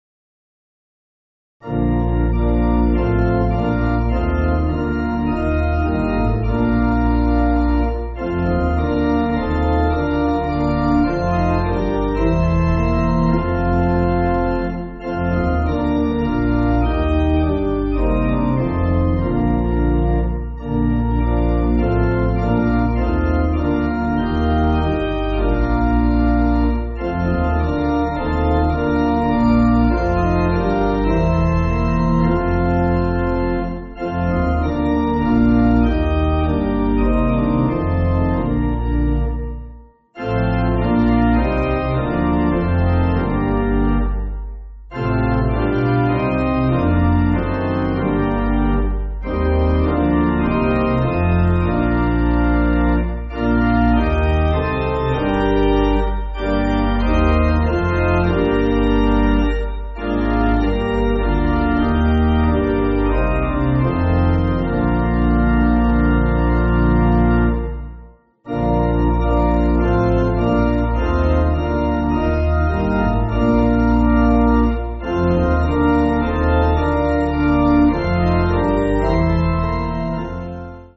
Organ
(CM)   2/Bb